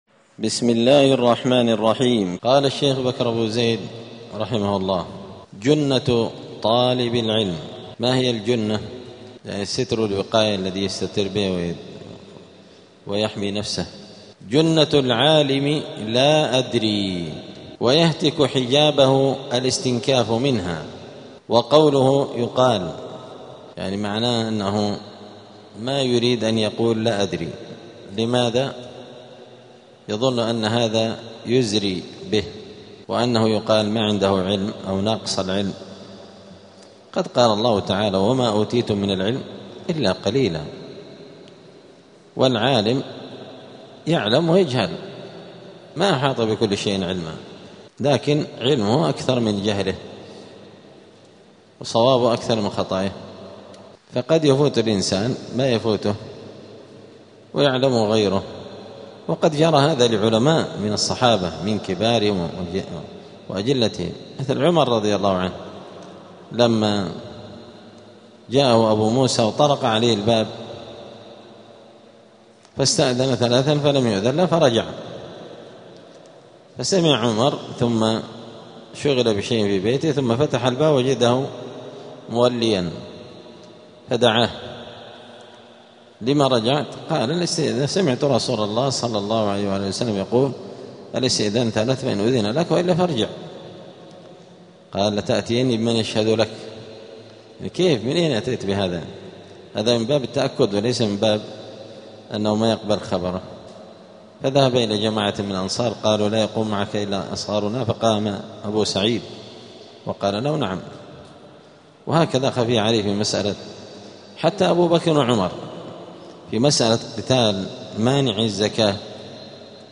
الجمعة 11 شعبان 1447 هــــ | الدروس، حلية طالب العلم، دروس الآداب | شارك بتعليقك | 14 المشاهدات
دار الحديث السلفية بمسجد الفرقان قشن المهرة اليمن